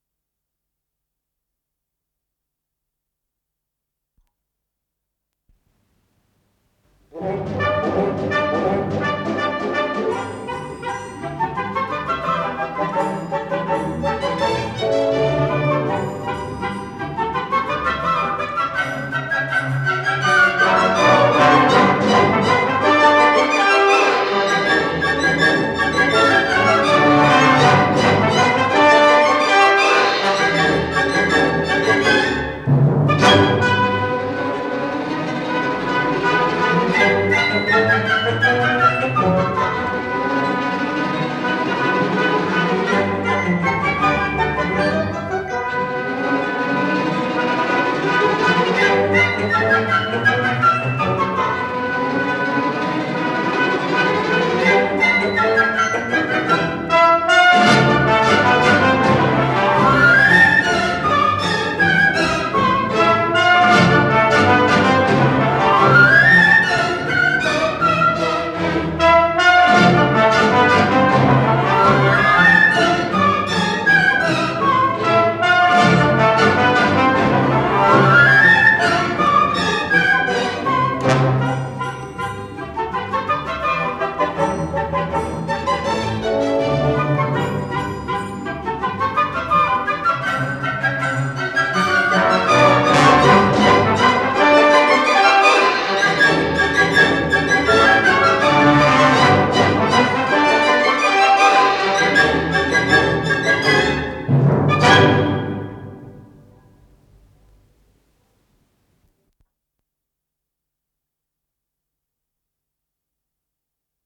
соль мажор
Сюита для симфонического оркестра